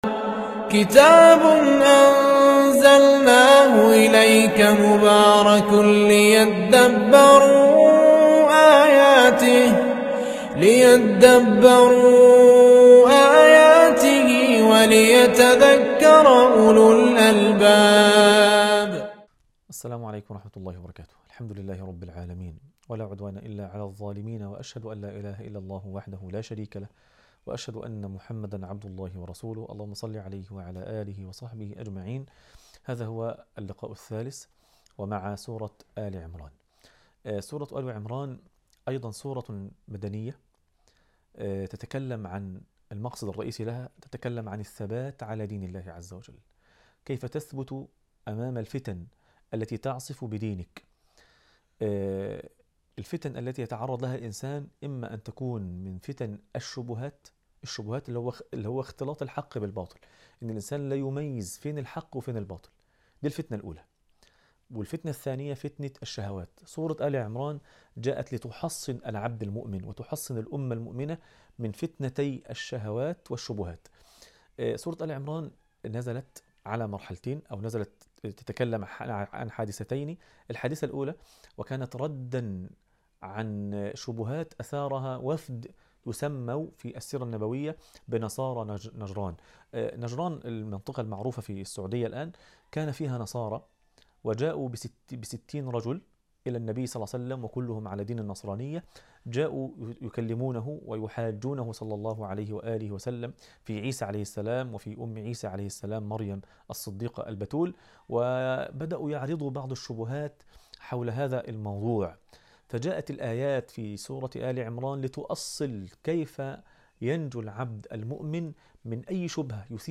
عنوان المادة الدرس الثالث - مقاصد السور و هدايات الآيات تاريخ التحميل السبت 20 سبتمبر 2025 مـ حجم المادة 10.89 ميجا بايت عدد الزيارات 55 زيارة عدد مرات الحفظ 29 مرة إستماع المادة حفظ المادة اضف تعليقك أرسل لصديق